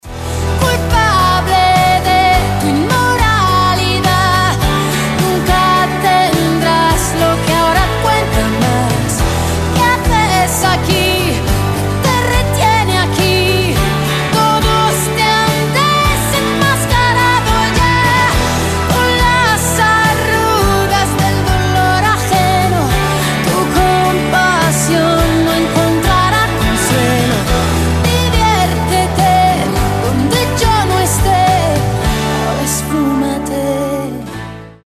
• Качество: 256, Stereo
громкие
vocal